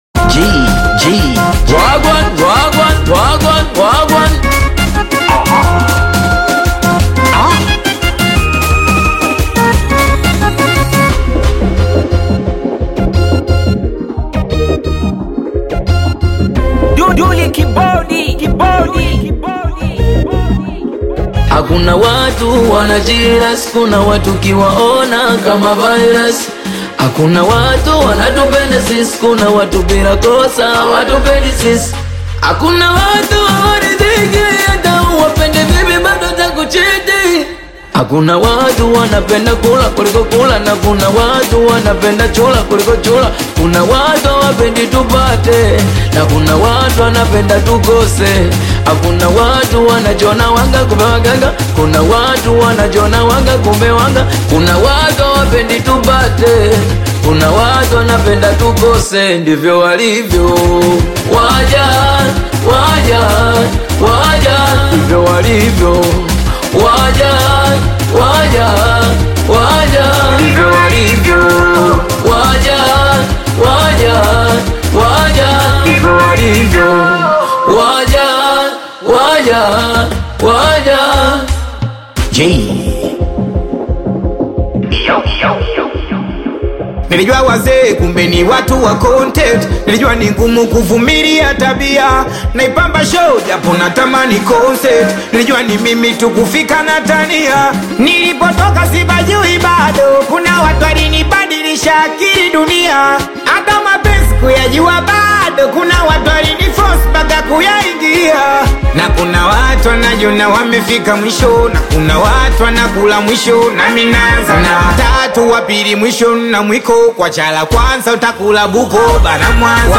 high-energy Tanzanian Singeli/Bongo Flava collaboration
fast-paced Singeli beats
energetic delivery and authentic urban storytelling